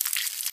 mob / silverfish / step4.ogg
step4.ogg